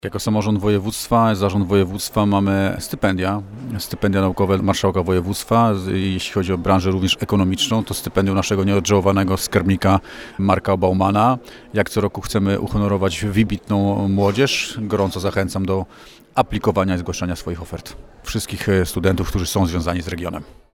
Do czego na naszej antenie zachęcał marszałek województwa – Marcin Kuchciński.